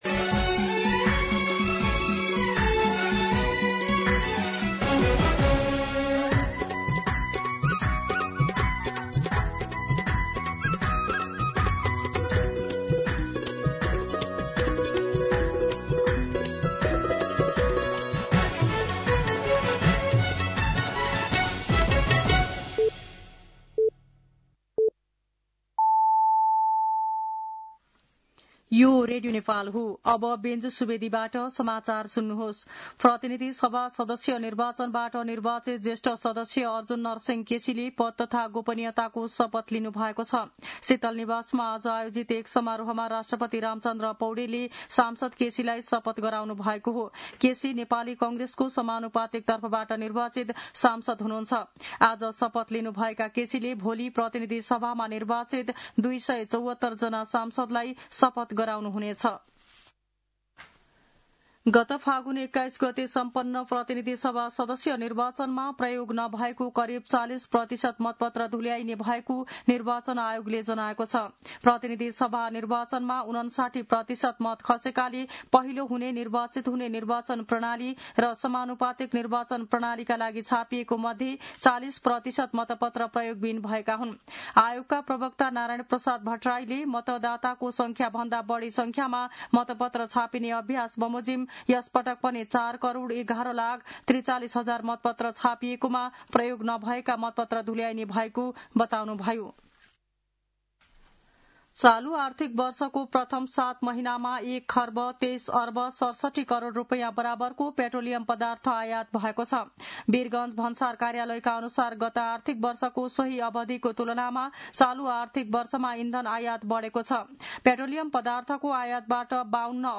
मध्यान्ह १२ बजेको नेपाली समाचार : ११ चैत , २०८२
12-pm-Nepali-News-5.mp3